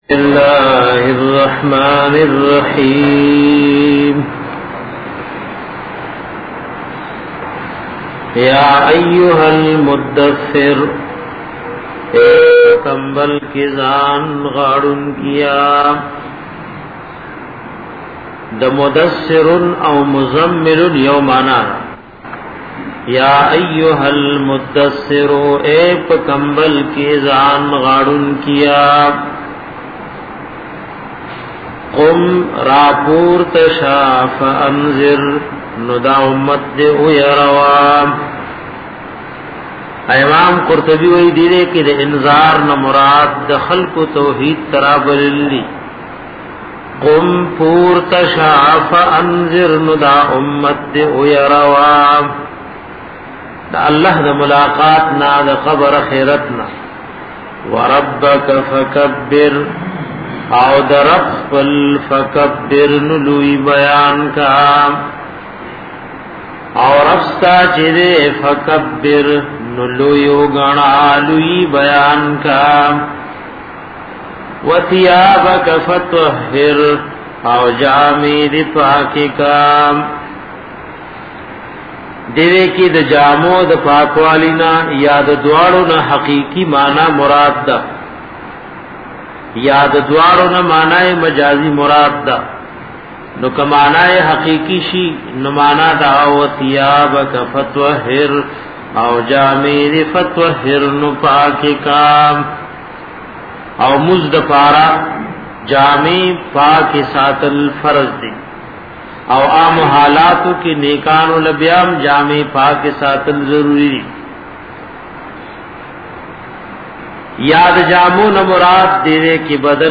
Darse-e-Quran